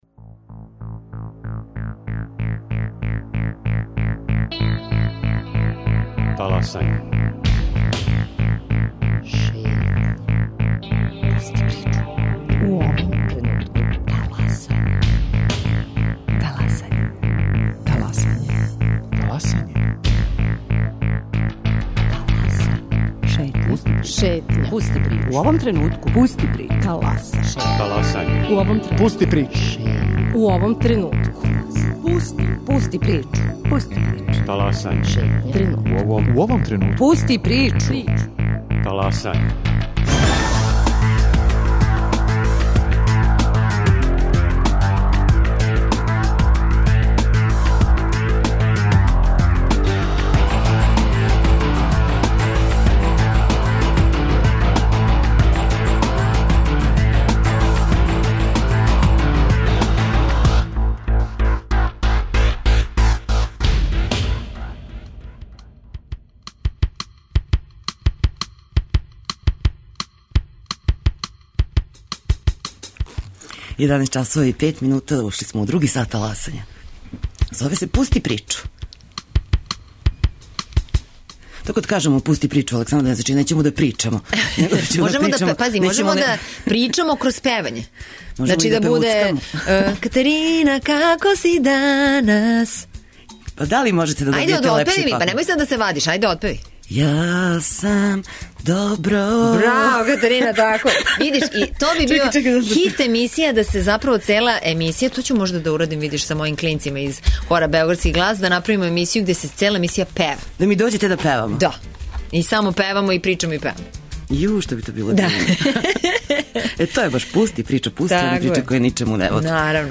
Гошћа Александра Ковач.